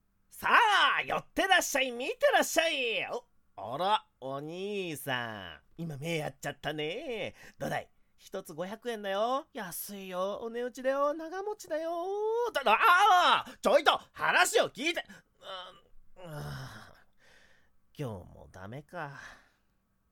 – セリフ –
② 怪しい商売人
怪しい商売人.mp3